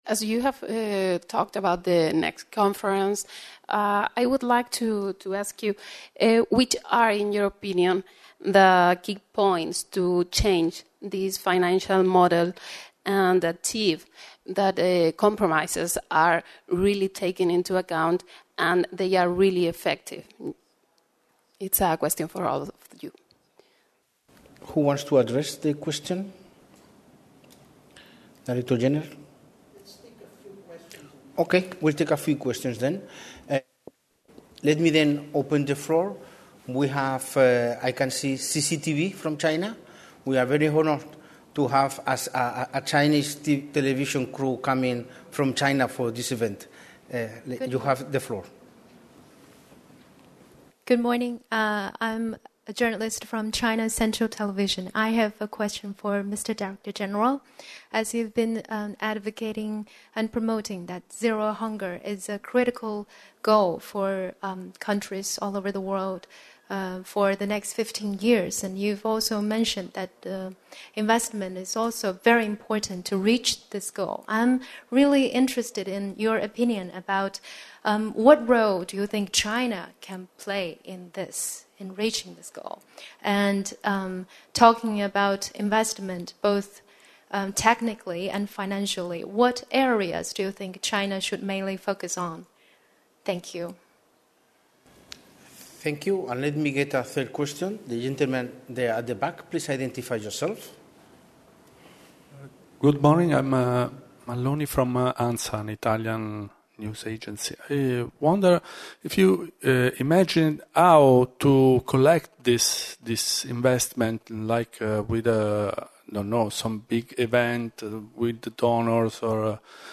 Questions and answers .
Zero-Hunger-report-Q-and-A.mp3